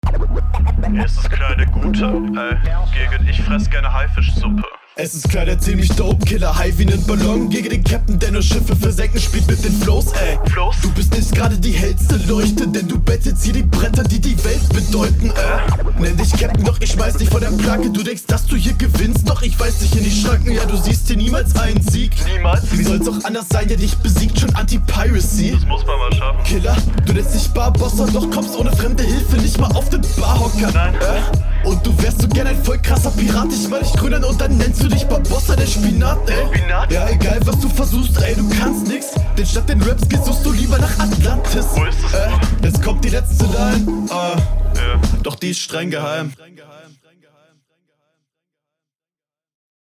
Guter Flow, Gute Soundquality, Beat rauscht mir zu sehr aber egal egal.